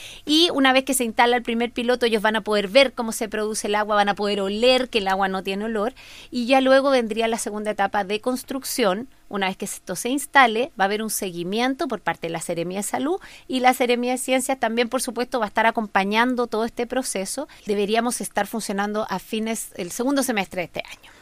En entrevista con La Radio, la seremi Maite Castro indicó que manejan un listado de 12 APR para elegir uno donde implementarlo, corriendo con ventaja hasta ahora la comunidad de Coz Coz en Panguipulli, si es que los usuarios del comité están de acuerdo.